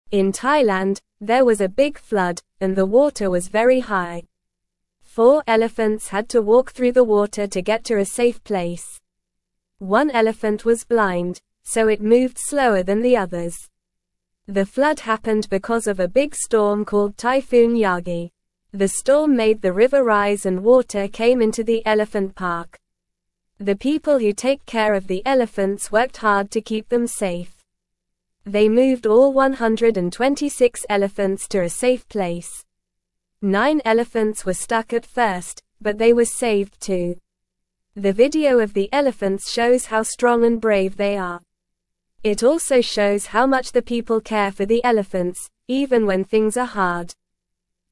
Normal
English-Newsroom-Beginner-NORMAL-Reading-Elephants-Brave-Flood-in-Thailand-People-Keep-Safe.mp3